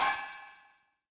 add drum sound samples from scratch 2.0
HiHatOpen(2)_22k.wav